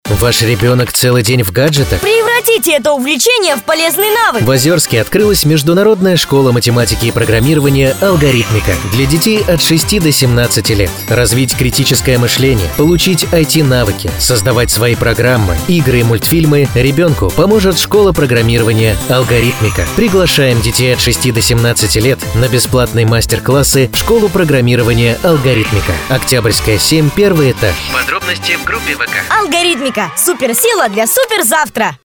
Муж, Рекламный ролик/Средний
Дикторская кабина, Lewitt LCT440 PURE, Audient iD4 MKII.